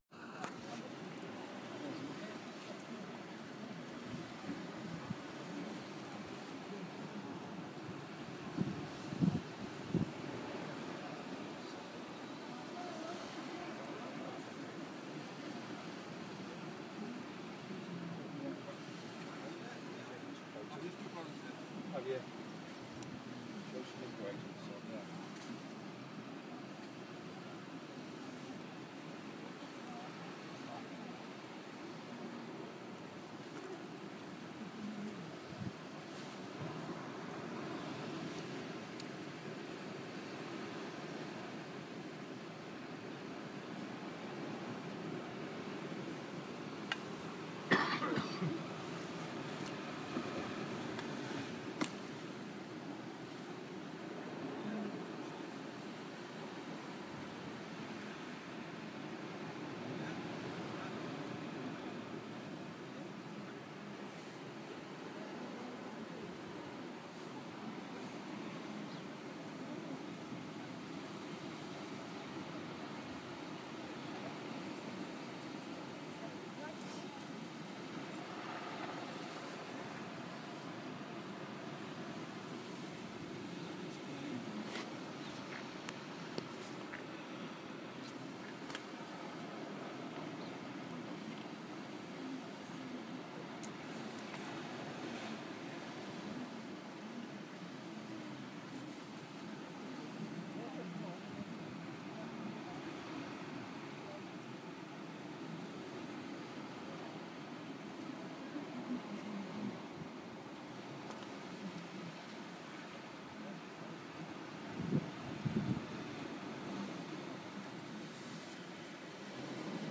Mullion Cove (Porthmellin), Cornwall